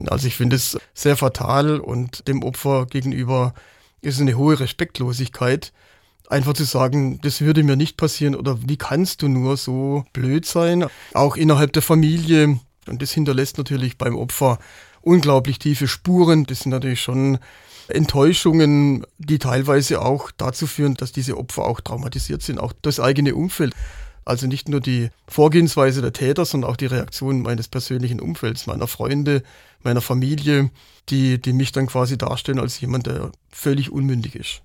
SWR-Interview